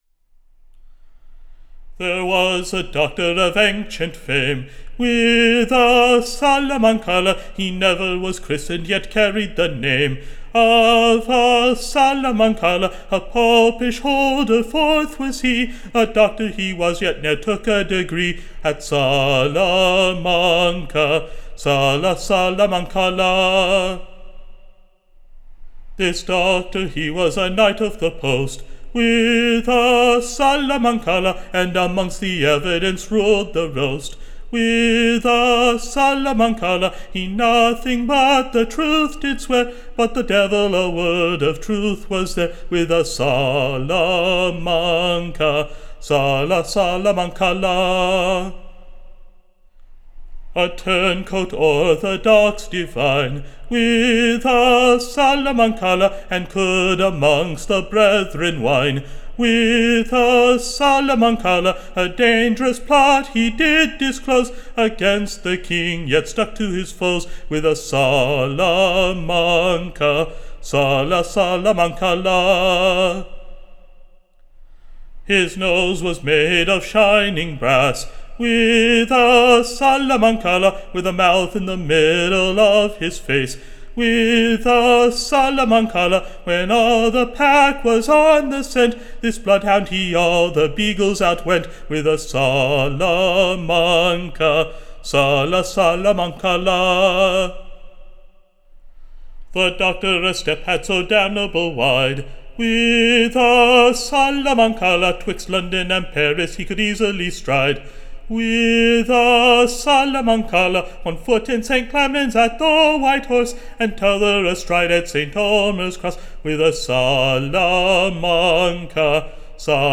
Recording Information Ballad Title Truth Tryumphant, / Over Perjury Rampant.